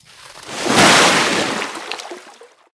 Sfx_creature_snowstalkerbaby_jump_water_01.ogg